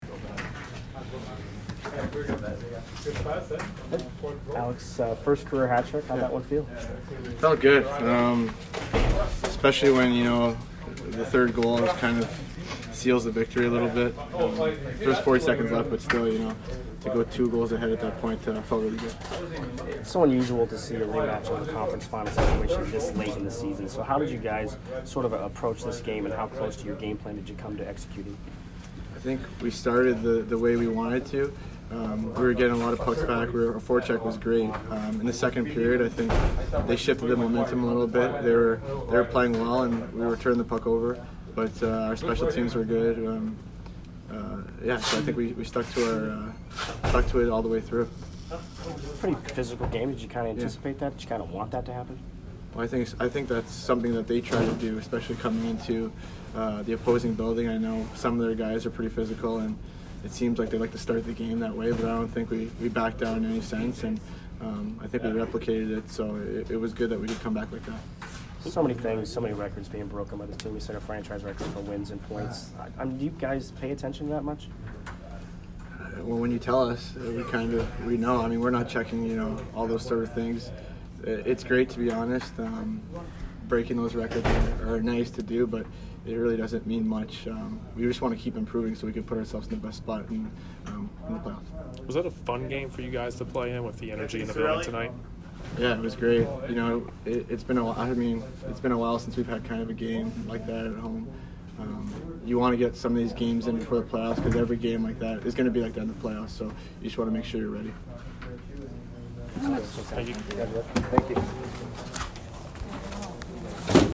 Alex Killorn post-game 3/16